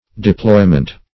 Deploy \De*ploy"\, Deployment \De*ploy"ment\, n. (Mil.)